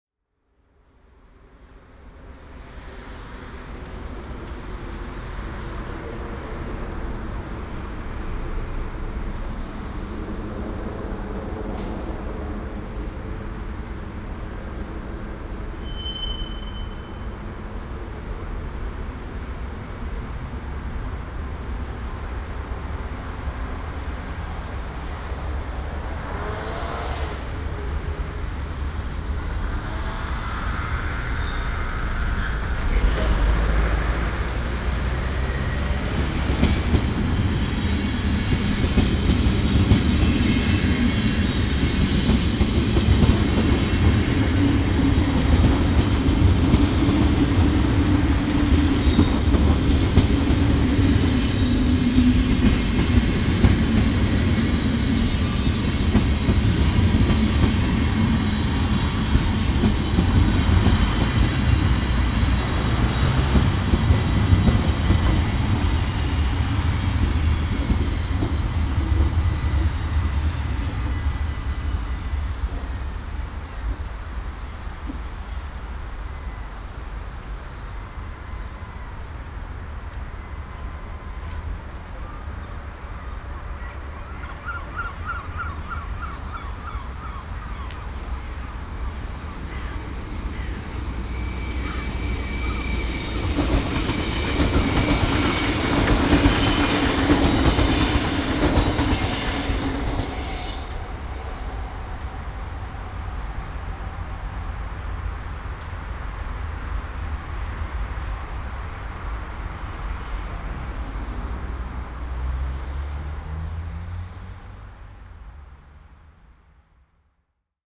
Cars and Trains Wegastraat
Cars, scooter and trains pass by. 9.30 in the morning. Binaural recording
Adres: Wegastraat, Binckhorst, Laak, The Hague, South Holland, Netherlands, 2516 BZ, Netherlands